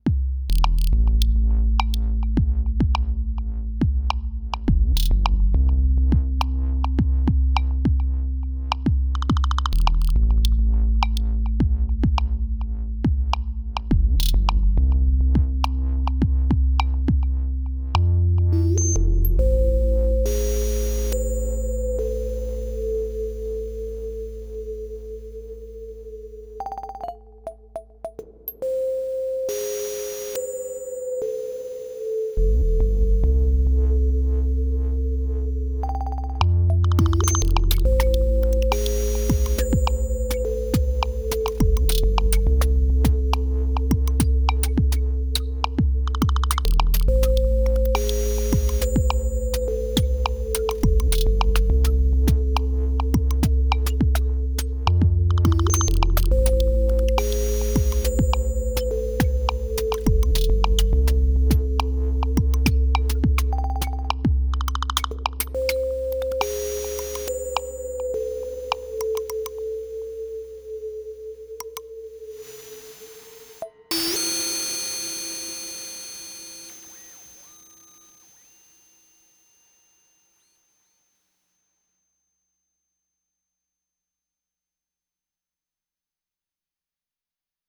i wanted to upload a couple of older Cycles jams on here, just for fun :slight_smile: